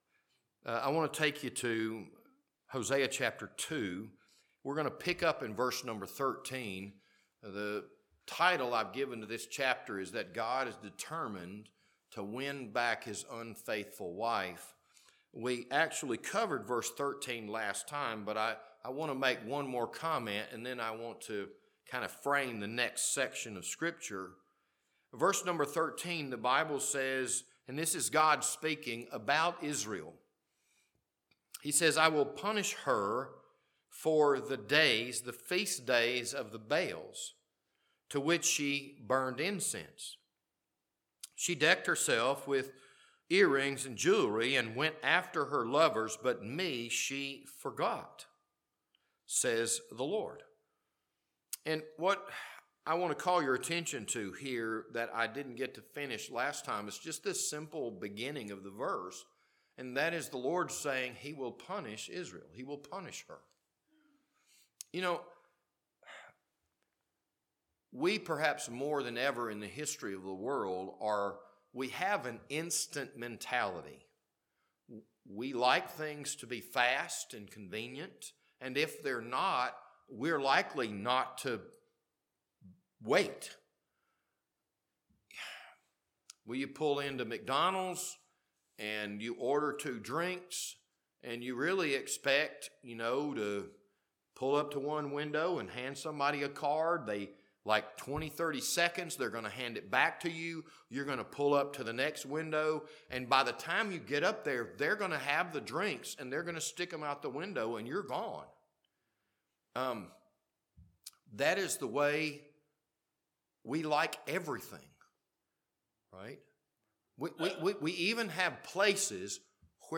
This Sunday evening sermon was recorded on March 9th, 2025.